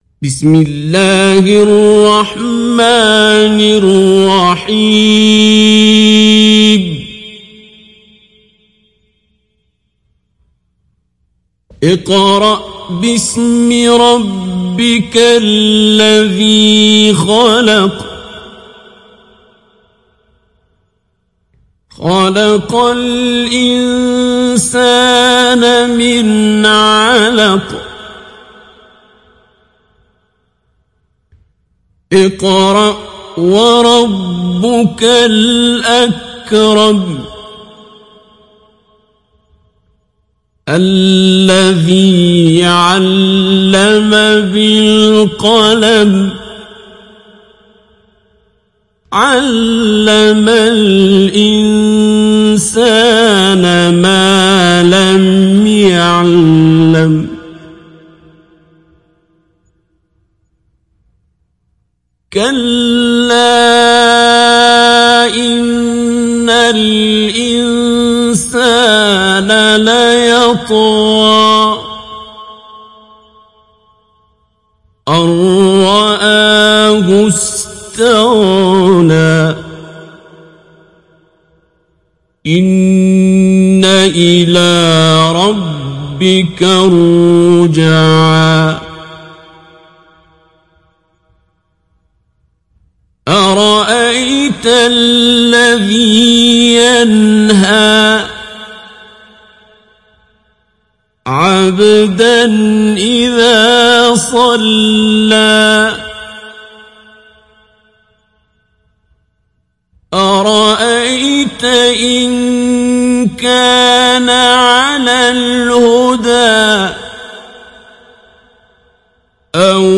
Surat Al Alaq mp3 Download Abdul Basit Abd Alsamad Mujawwad (Riwayat Hafs)